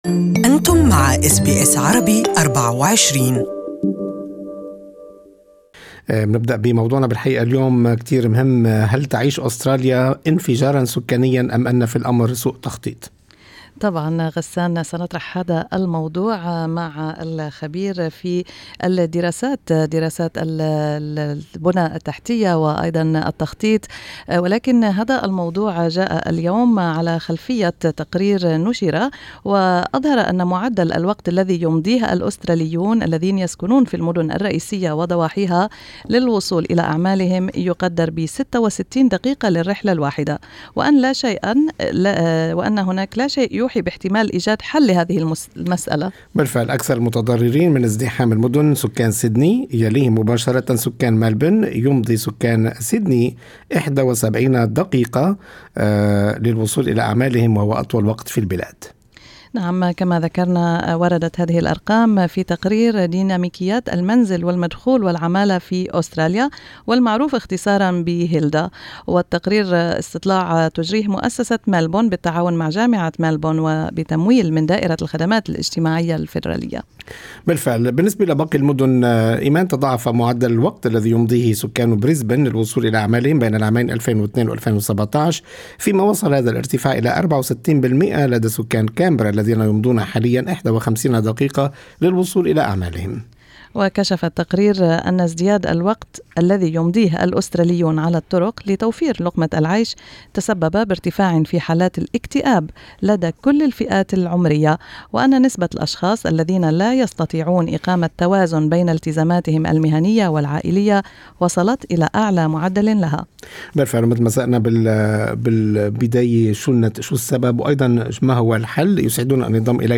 استمعوا إل اللقاء الكامل معه عبر الرابط الصوتي أعلاه.